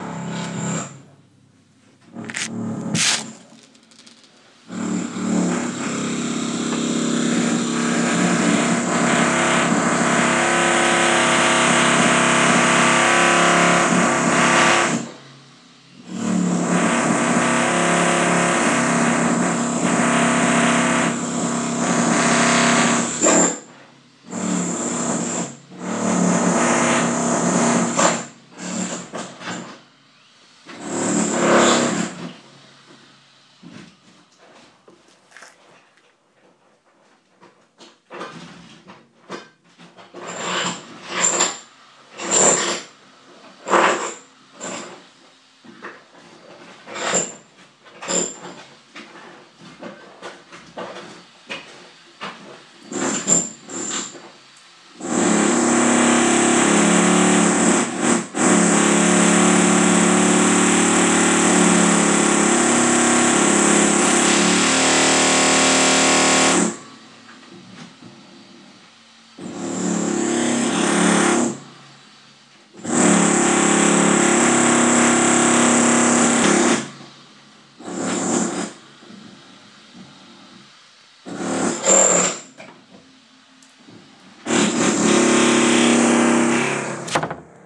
Officebuildingnoise
sadly the noise we had to live with today …..endless work in our building ….not great for audio heads like us